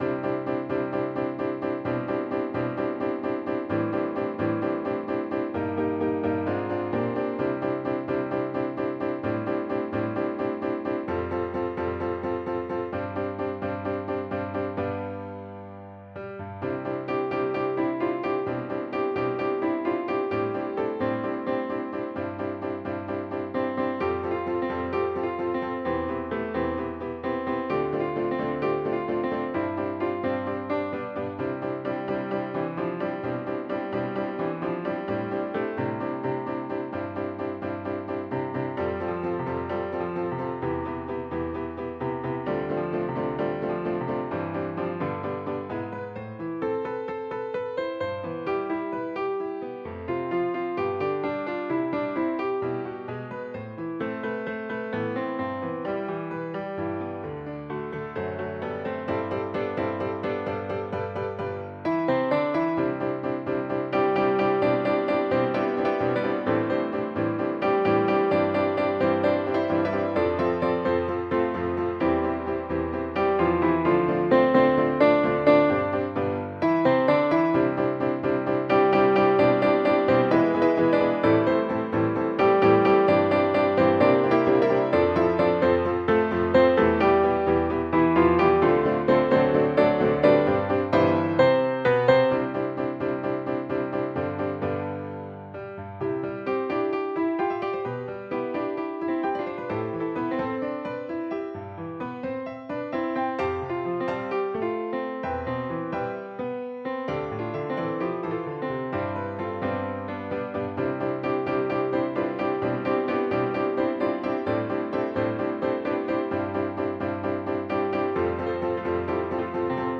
SATB mixed choir and piano
世俗音樂